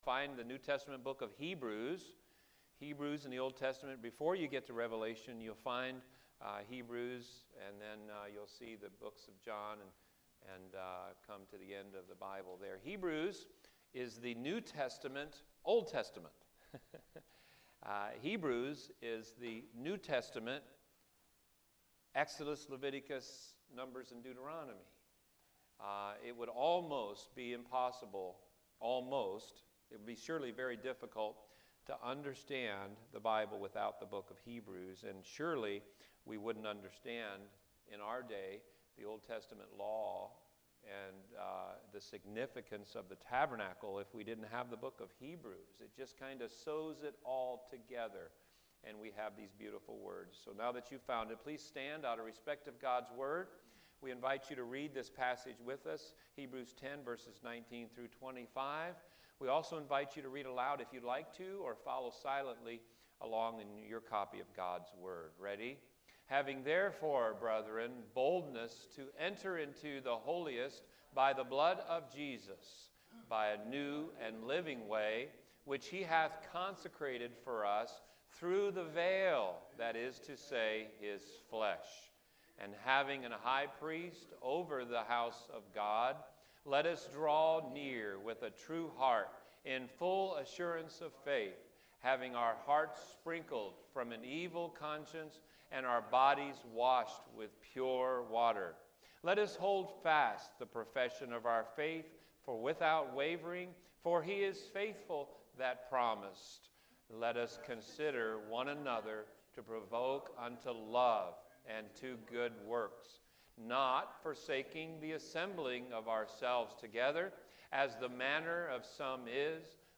What an inspiring sermon Pastor!